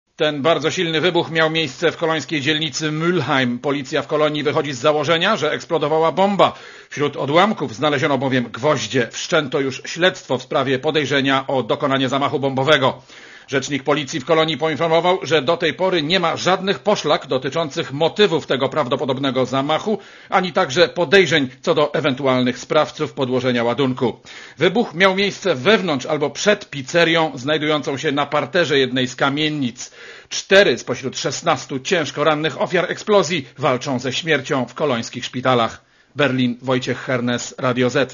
Korespondencja z Niemiec